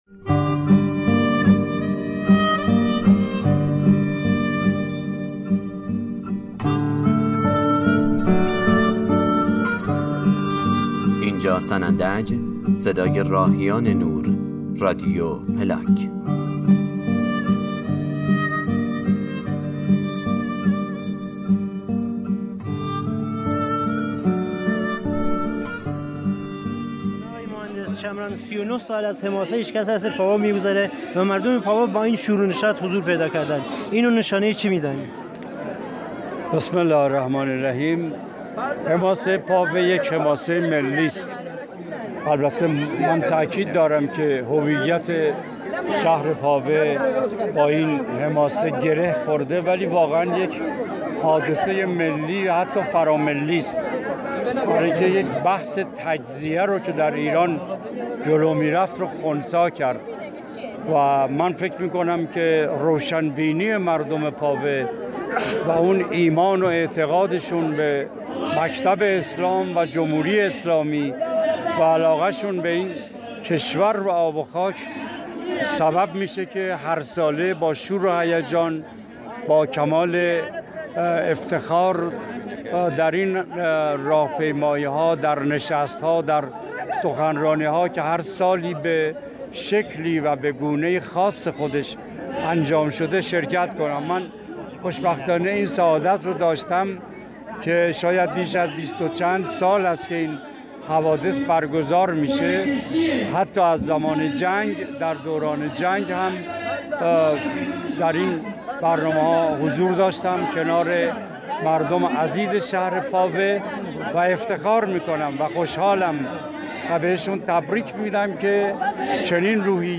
بخشی از صوت مصاحبه ای از ( سنندج، صدای راهیان نور ، رادیو پلاک ) است.